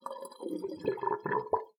bathroom-sink-05
bath bathroom bubble burp click drain dribble dripping sound effect free sound royalty free Sound Effects